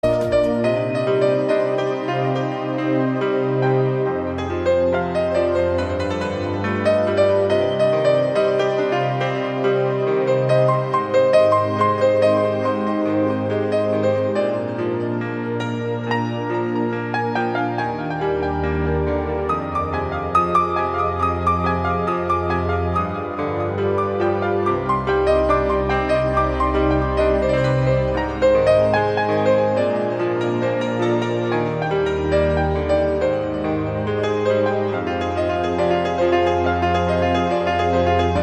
Ремикс известной мелодии